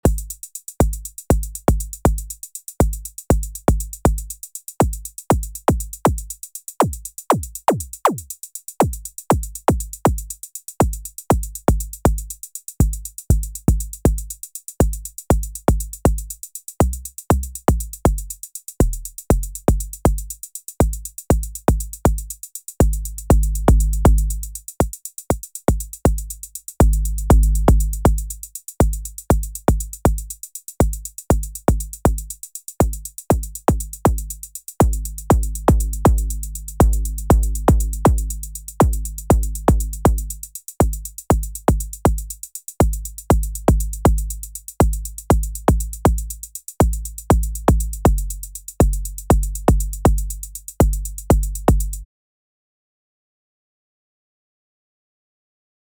ok, just did a very quick recording, tweaking some parameters on the kick that I designed before, so you can decide for yourself…